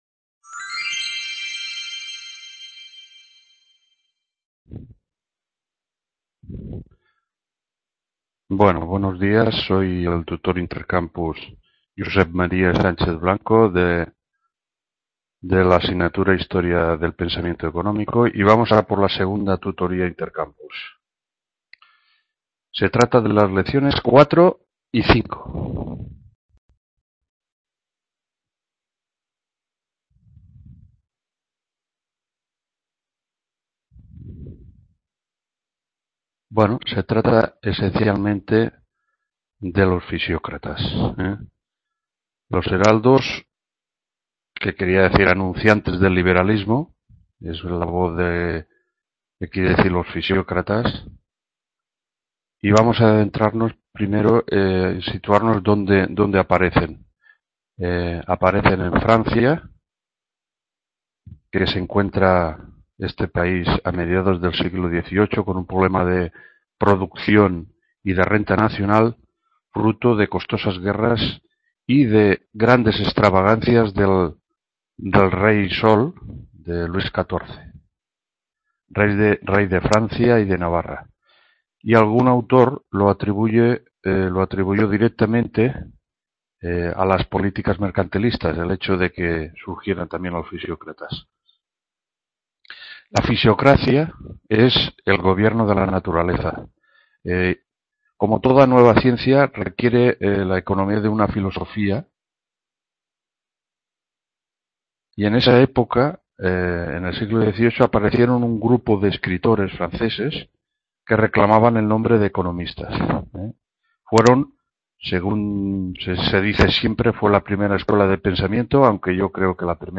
2ª Tutoría Intercampus Historia del Pensamiento…